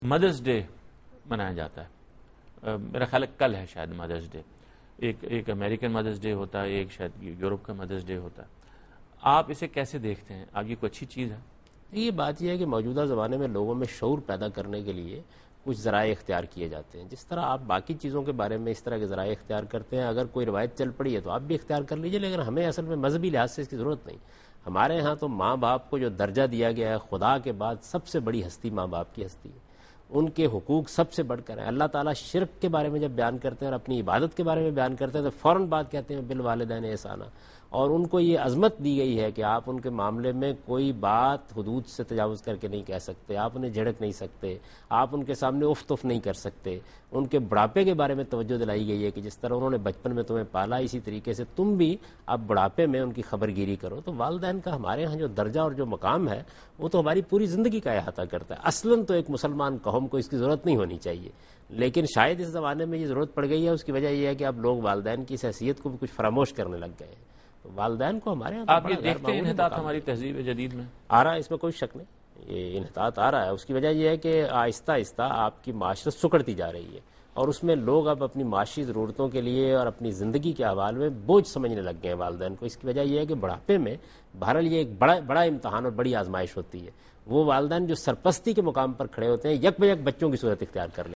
Answer to a Question by Javed Ahmad Ghamidi during a talk show "Deen o Danish" on Duny News TV